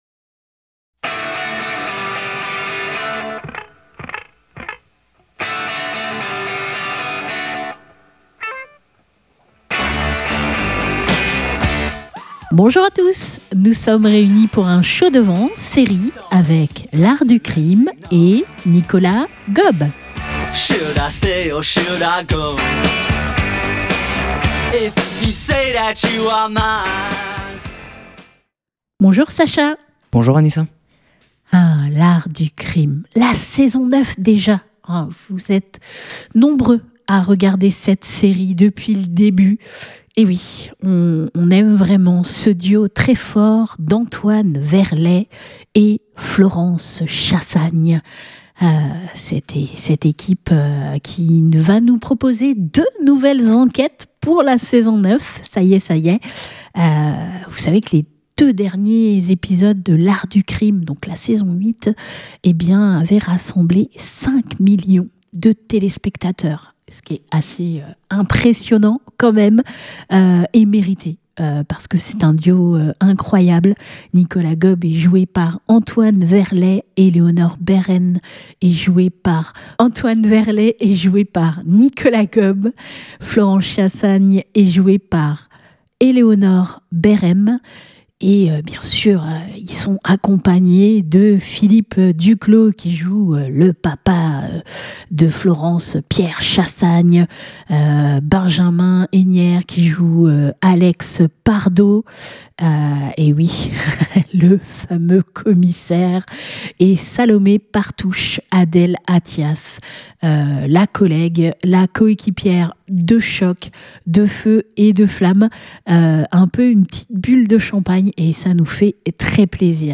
Une plongée au cœur des tableaux de Cézanne et de Jacques-Louis David. C'est avec plaisir que nous en parlons au téléphone avec le talentueux Nicolas Gob.